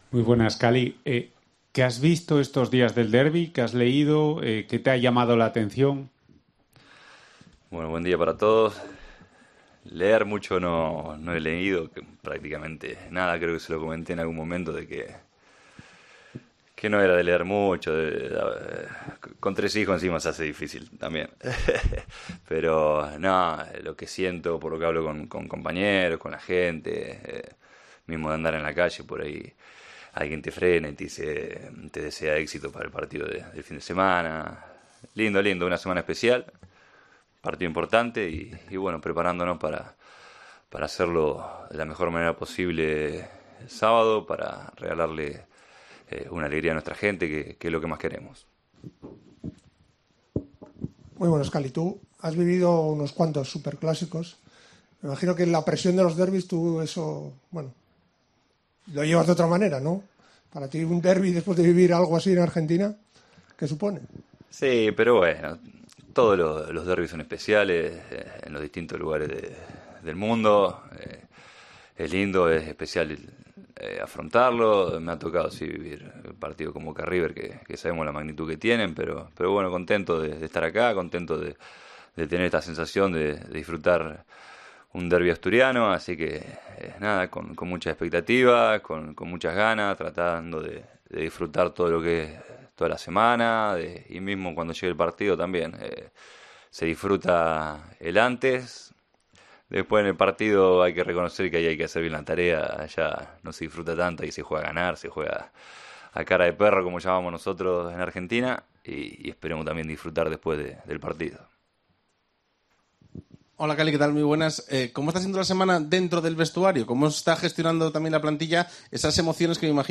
Cali Izquierdoz, central argentino del Real Sporting, compareció en sala de prensa a dos días para el derbi asturiano para abordar la actualidad que rodea a su primer derbi.